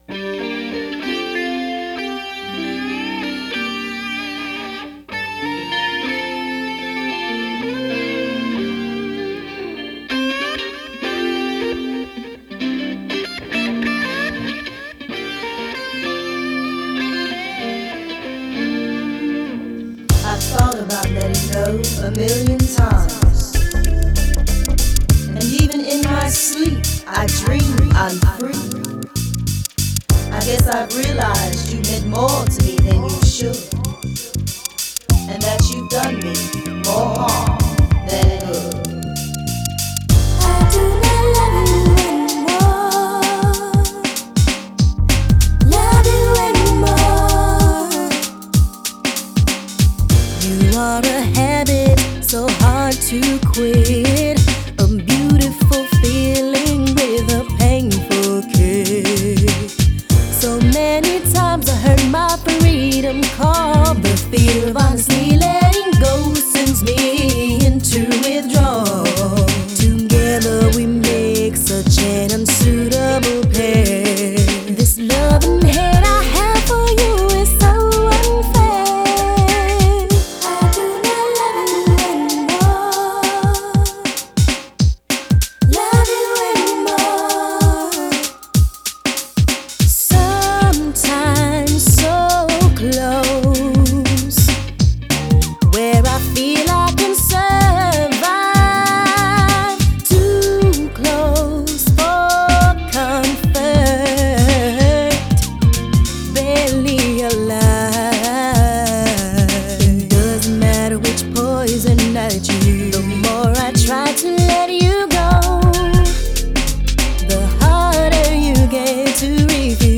female duo group